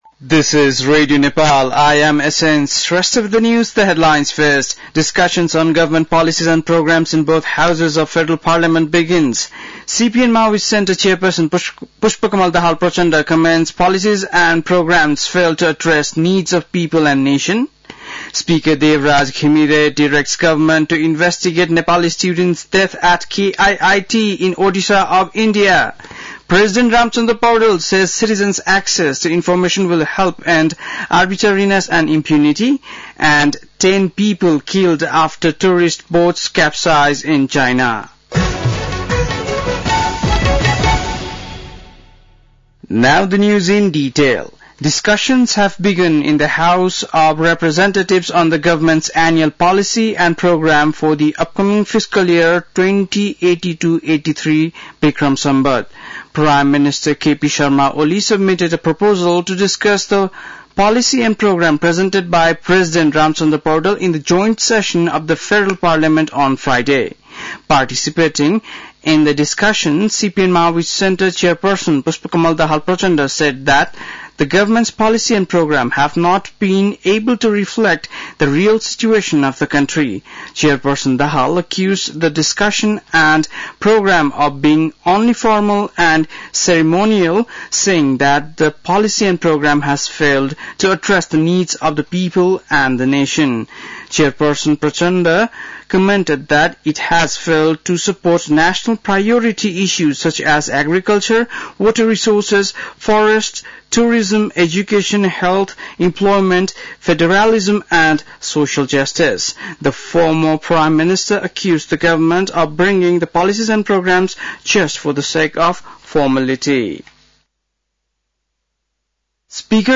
बेलुकी ८ बजेको अङ्ग्रेजी समाचार : २२ वैशाख , २०८२
8-pm-english-news-1.mp3